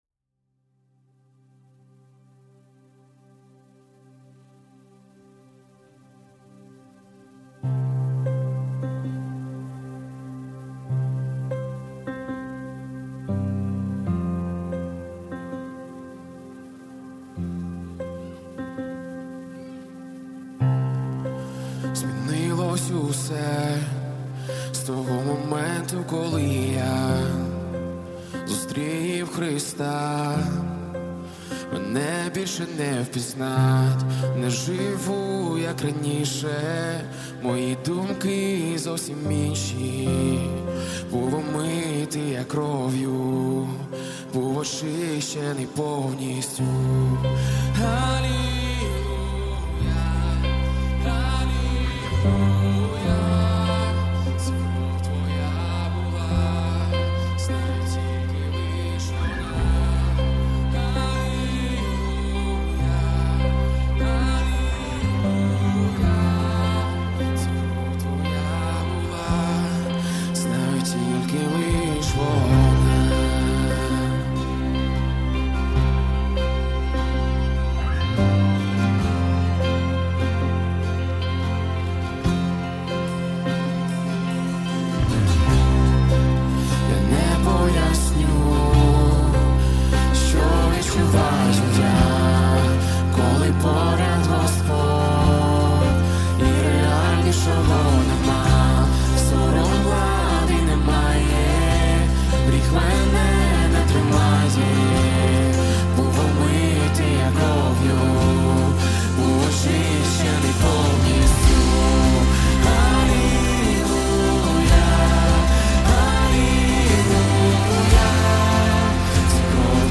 Worship Music
86 просмотров 86 прослушиваний 0 скачиваний BPM: 75